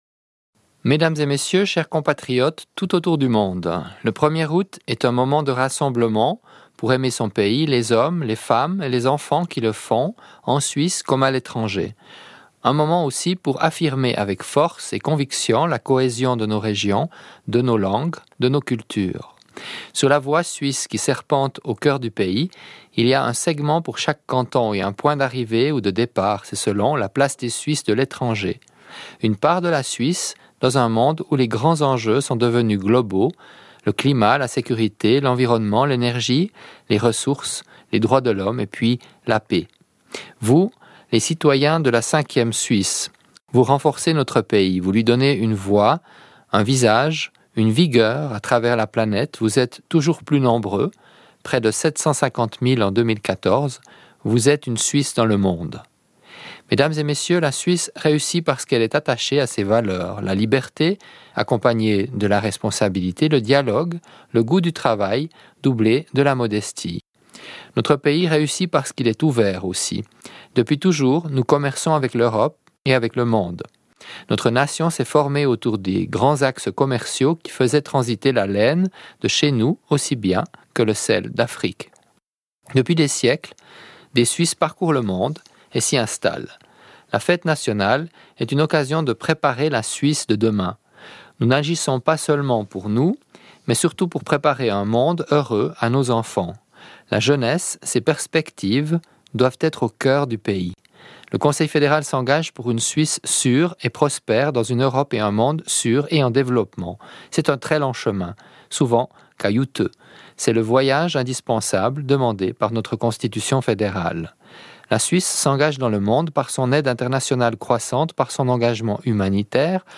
Allocution à l’intention des Suisses et des Suissesses de l’étranger prononcée par Didier Burkhalter, président de la Confédération, le 1er août 2014, à l’occasion de la fête nationale.